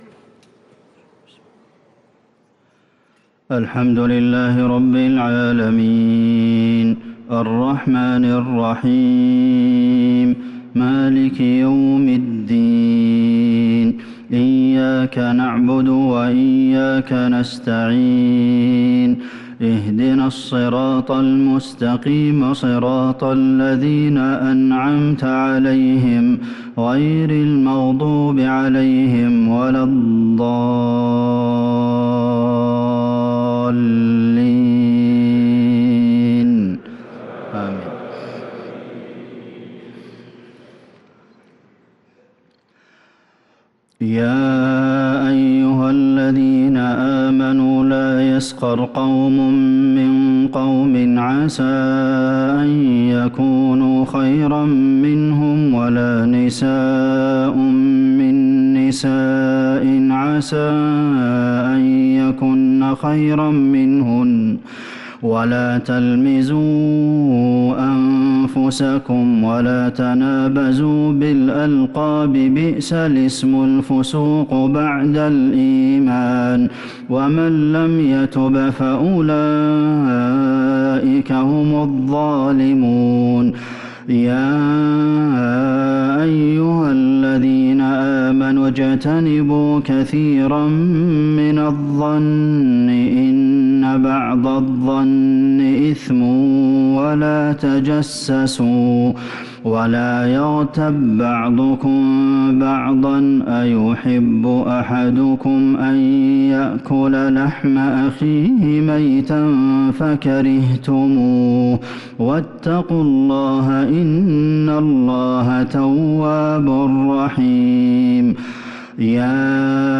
صلاة العشاء للقارئ عبدالمحسن القاسم 15 ذو الحجة 1443 هـ
تِلَاوَات الْحَرَمَيْن .